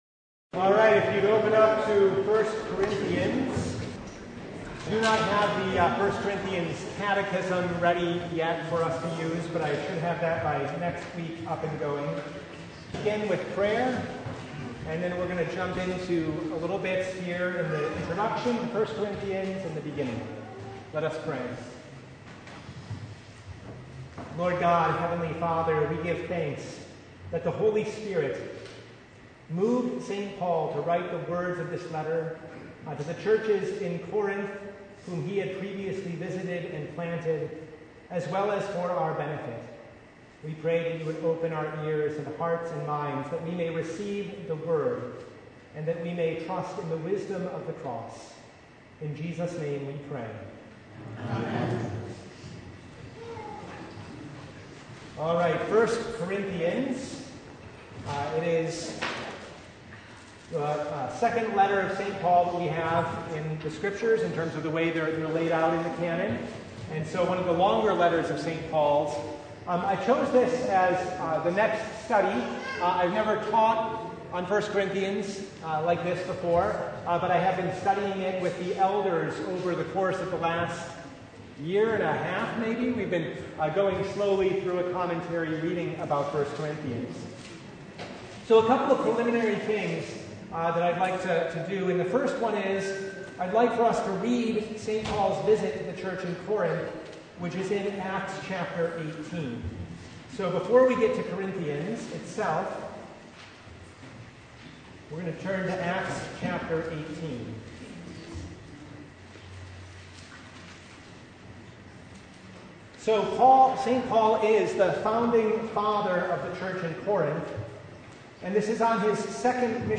1 Corinthians 1:1-9 Service Type: Bible Hour Topics: Bible Study « The Fifth Sunday in Martyrs’ Tide